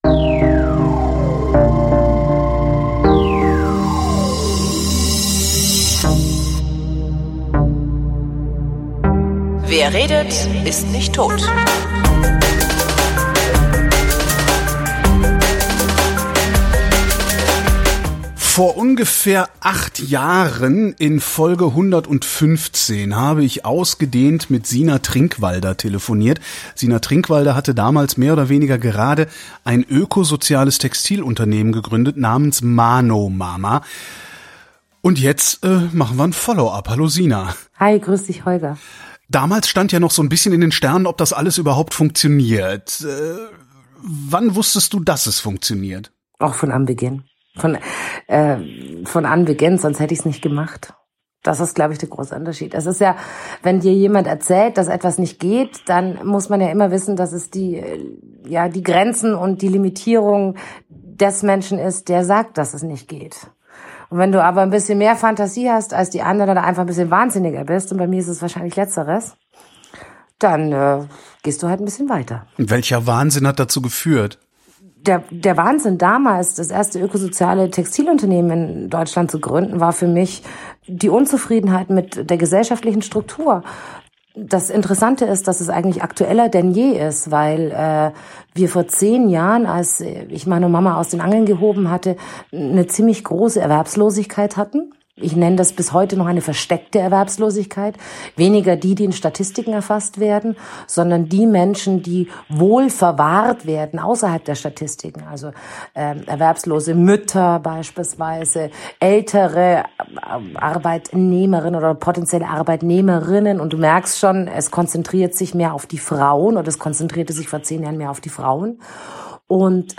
nochmal miteinander zu telefonieren. Die Aufnahme ist unter erschwerten Bedingungen entstanden